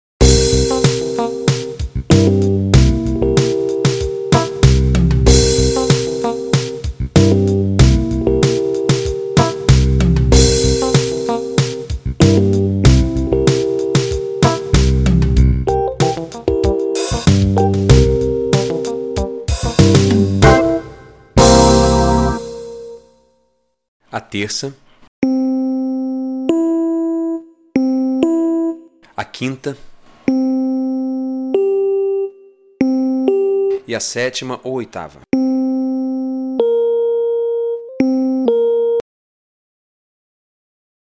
Você vai ouvir o acorde tocado por uma banda e, em seguida, nó vamos desmembrá-lo nas notas que o compôe.